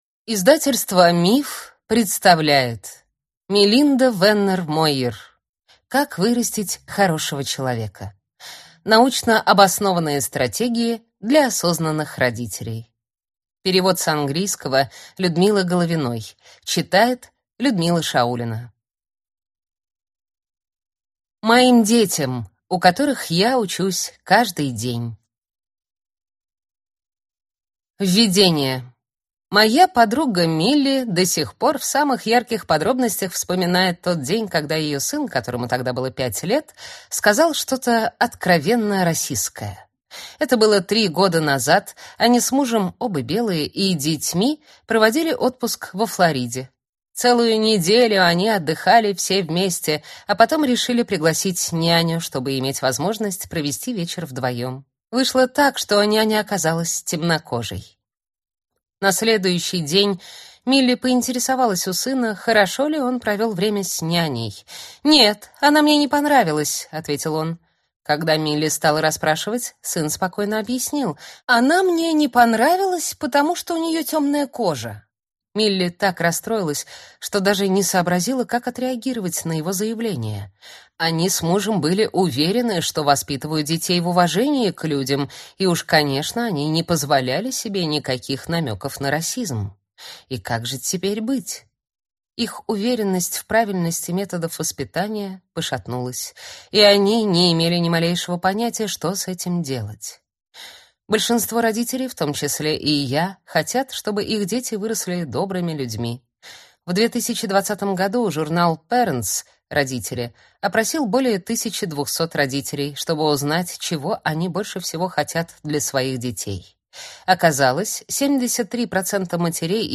Аудиокнига Как вырастить хорошего человека. Научно обоснованные стратегии для осознанных родителей | Библиотека аудиокниг